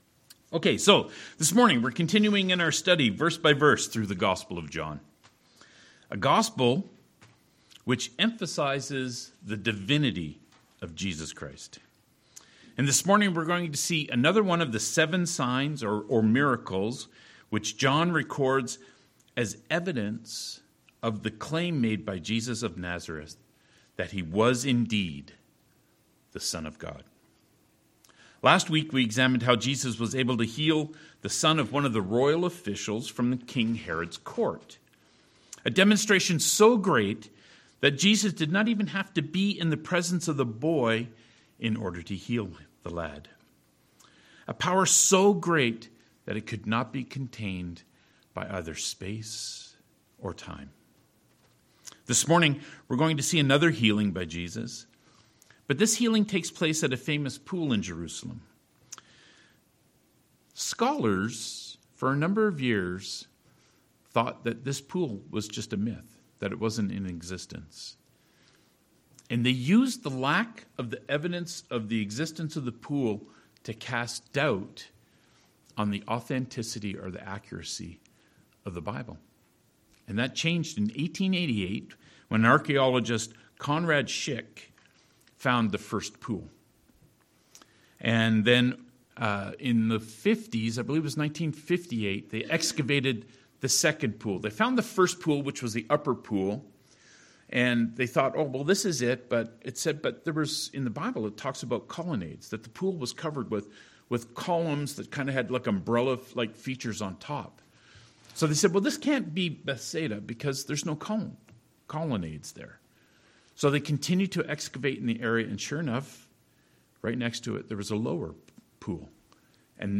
John "So That You May Believe" Passage: John 5: 1-15 Service Type: Sermons « Faith or Understanding?